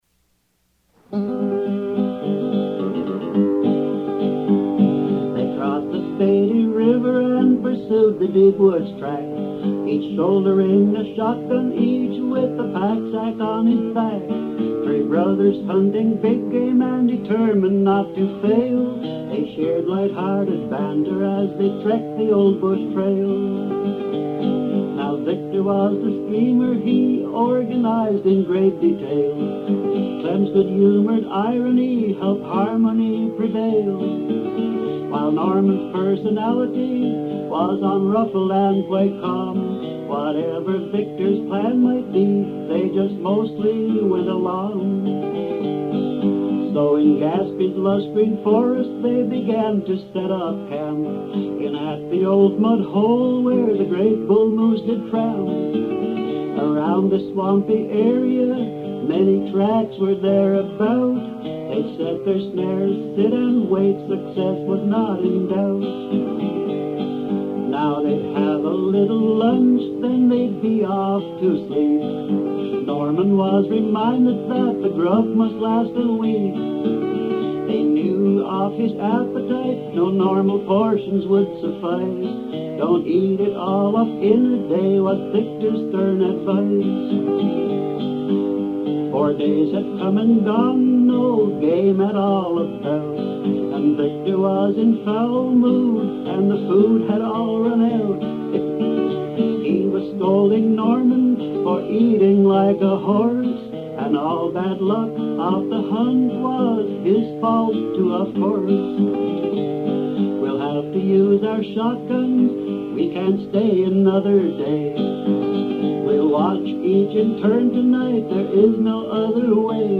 Original Song
and was a prolific poet and songwriter, modelling his sound after singers like Jimmie Rodgers, Wilf Carter, Hank Williams, and Hank Snow.